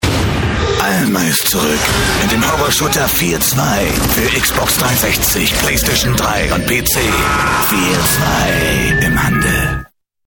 der Sprecher mit warmer, sonoriger und ausdrucksstarker Stimme für Werbung, Voice-off/-over, Hörbücher, Synchron und mehr...
Sprechprobe: Sonstiges (Muttersprache):
english (us) voice over artist